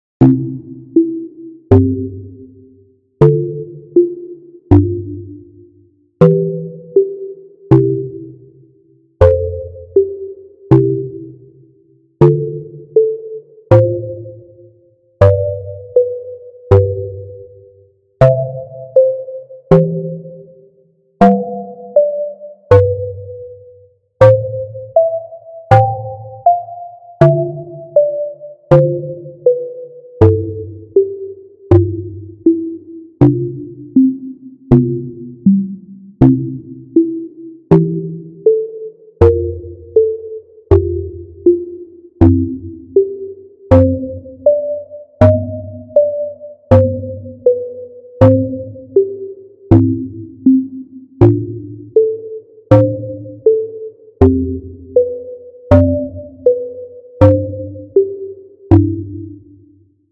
思い出とか夢の中とか。ループ対応。
BPM80